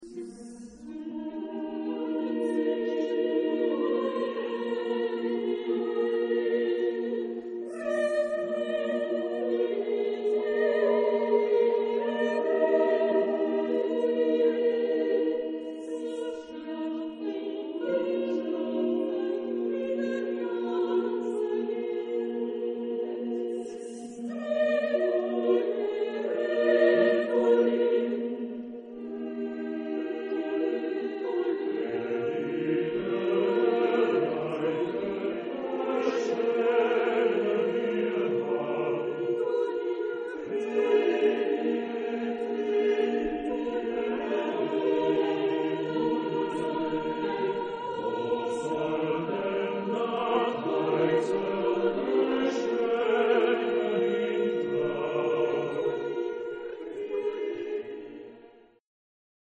Genre-Style-Forme : Populaire ; Profane
Type de choeur : SSAATTB  (7 voix mixtes )
Tonalité : mi bémol majeur